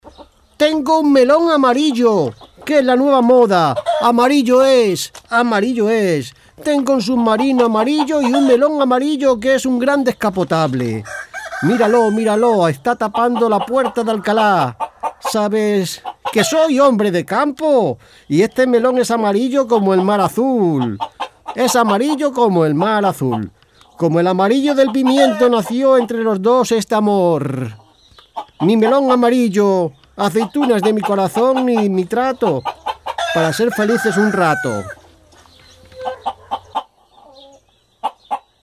locutor, voiceover
guiones-humor-melon-locutor-voiceover.mp3